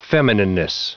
Prononciation du mot feminineness en anglais (fichier audio)
Prononciation du mot : feminineness